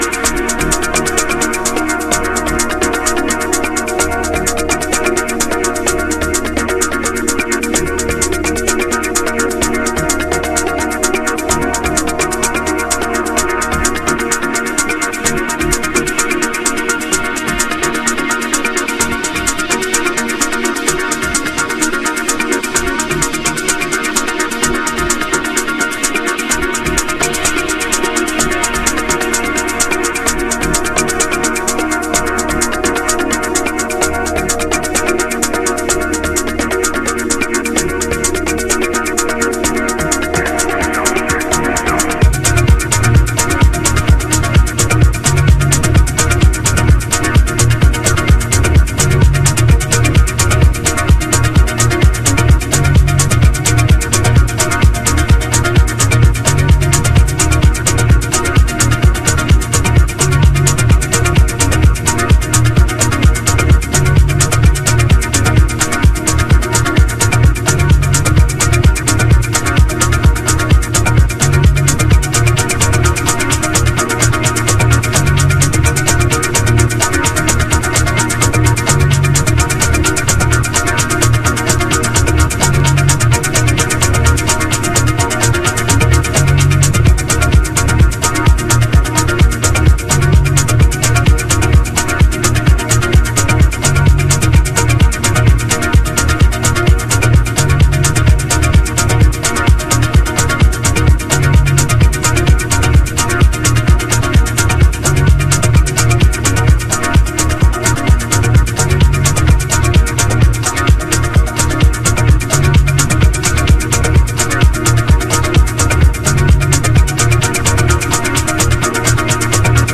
独創的な凹凸、奥深いイーヴンキックの世界。
House / Techno